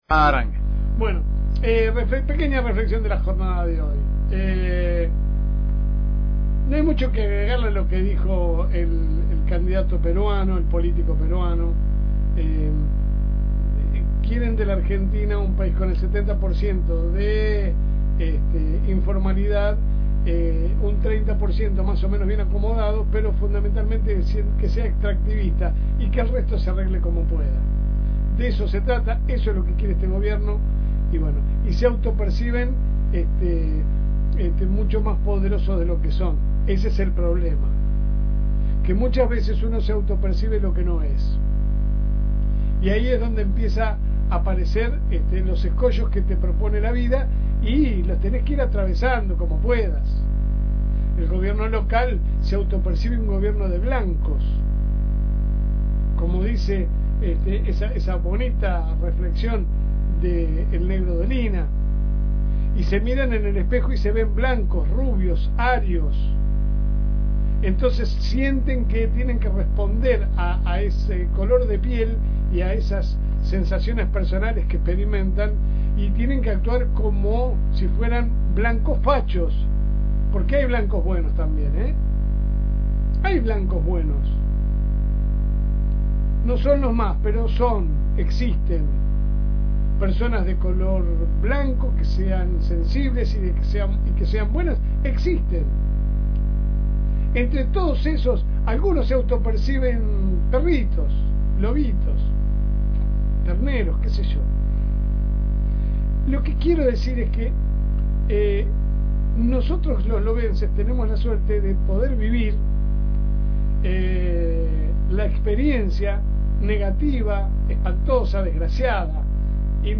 La editorial a continuación: